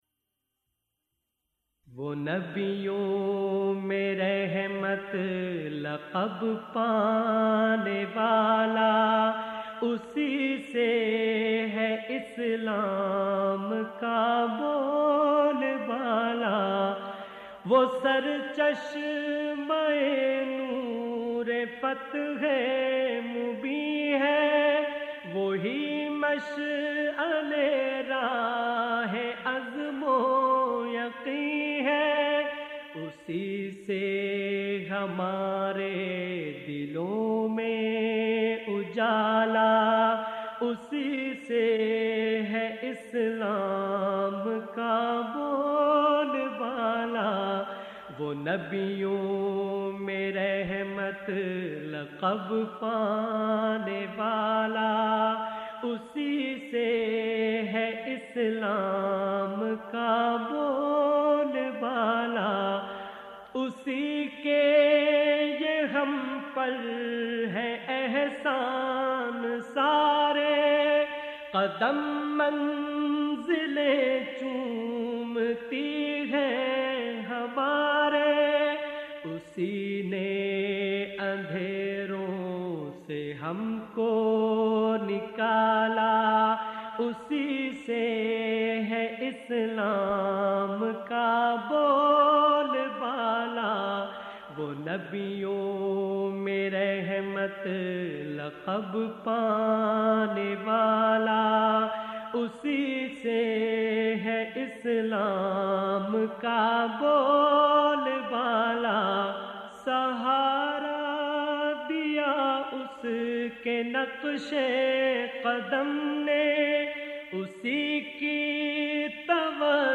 نعت رسول مقبول ص